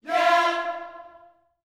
YEAH F 4B.wav